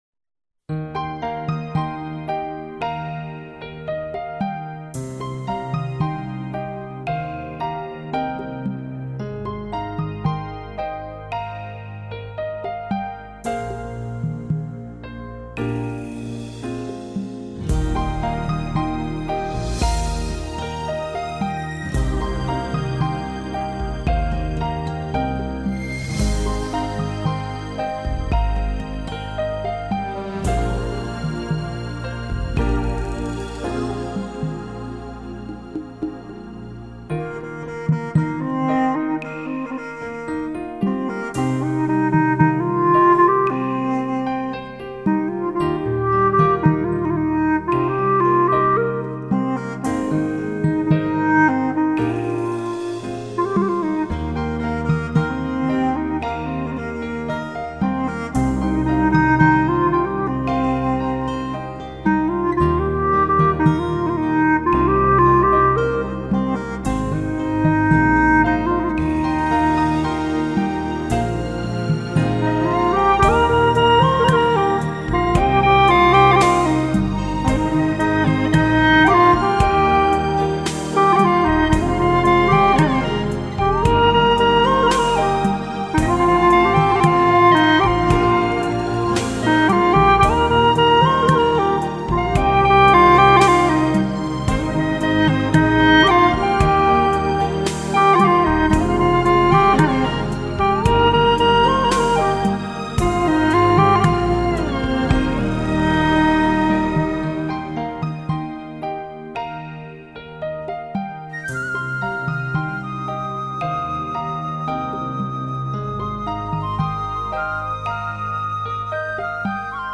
调式 : 降E 曲类 : 流行